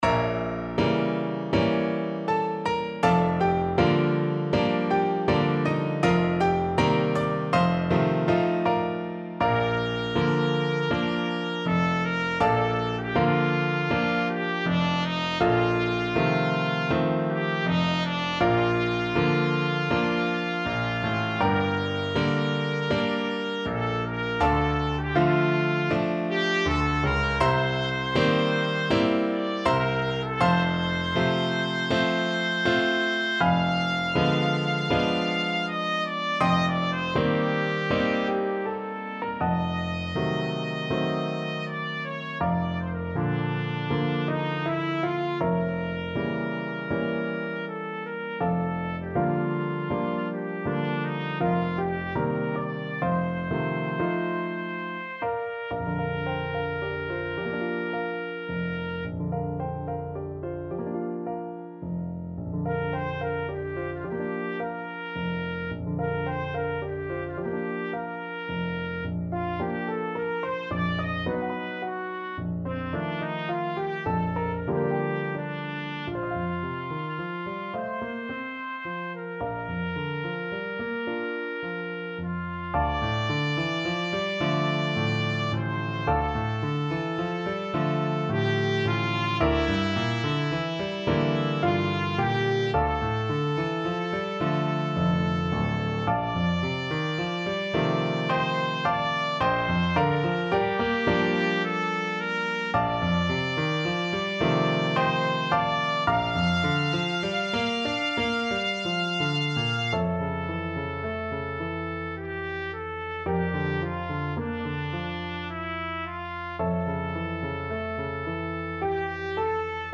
4/4 (View more 4/4 Music)
~ = 80 Allegretto moderato, ma non troppo
Classical (View more Classical Trumpet Music)